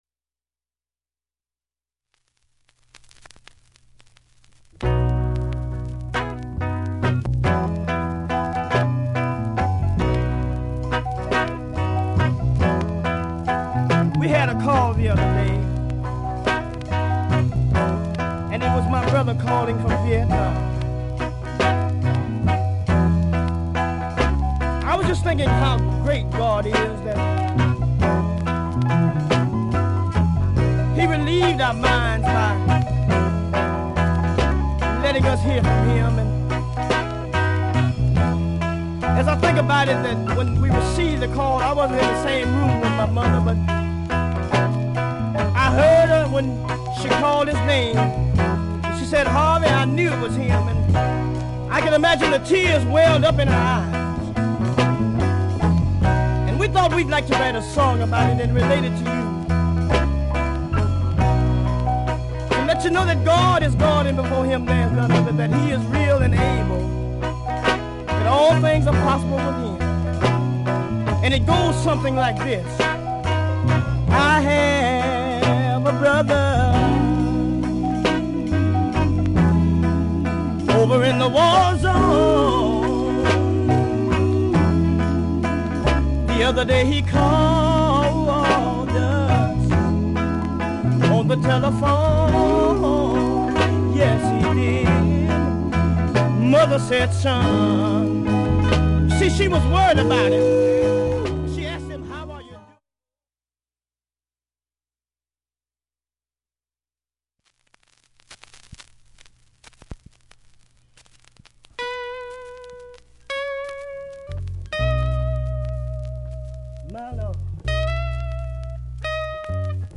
◆盤質Ａ面/ほぼVG+スレキズ少ないです。
◆あまり問題ない感じです。音圧あります。
音質目安にどうぞ ◆ステレオ針での試聴です。